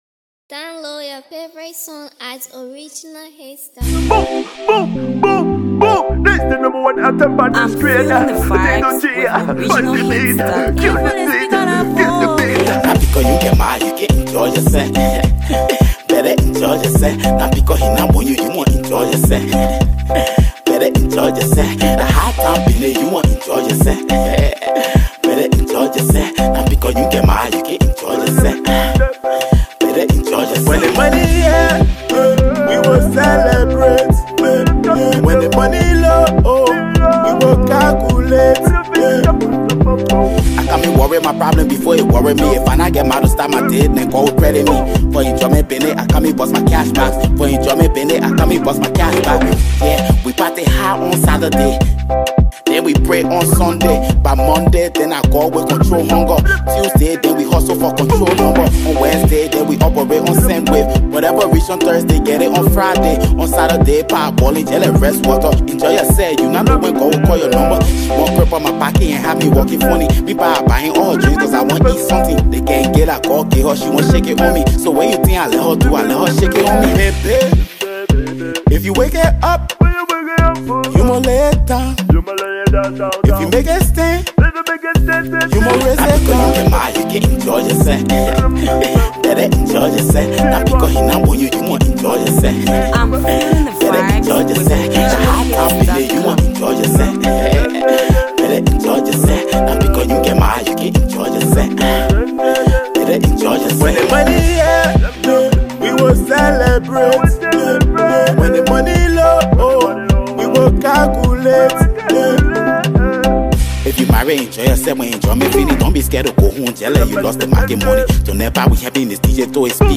street jam